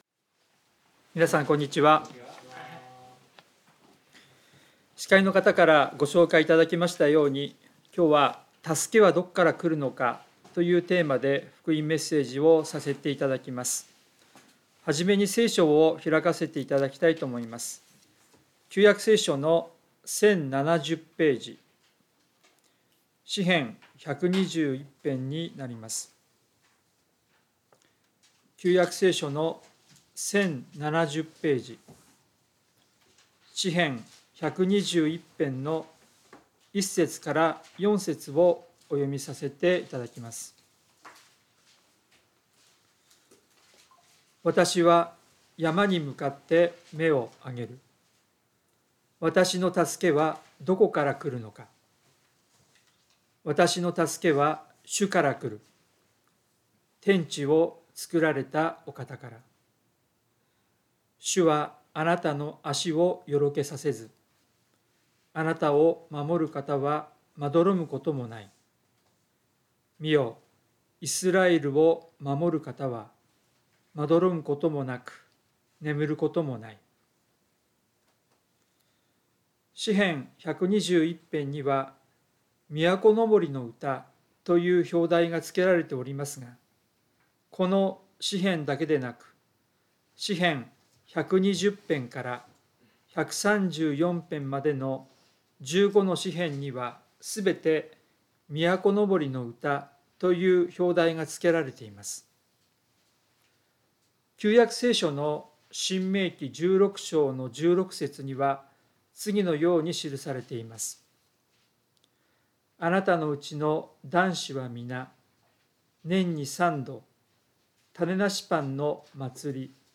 聖書メッセージ No.291